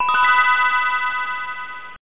GameStart.mp3